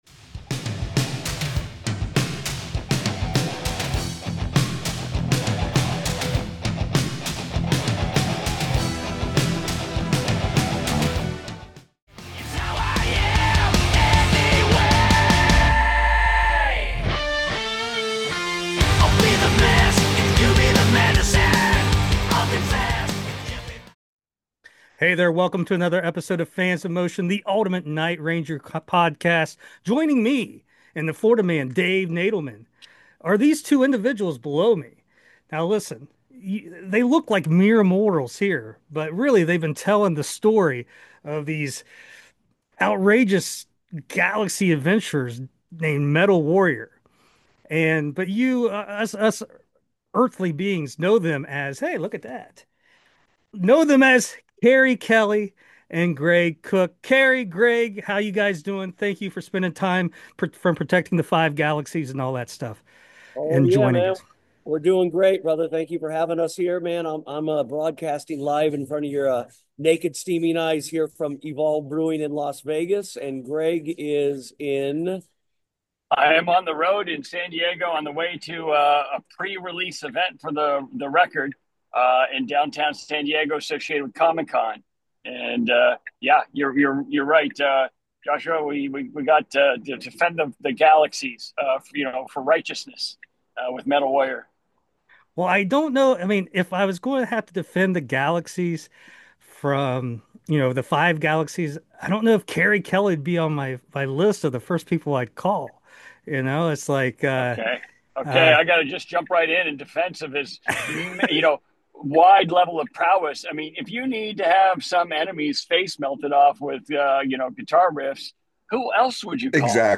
Keri Kelli & Greg Koch stop by the podcast to share everything there is to know about Metal Warrior!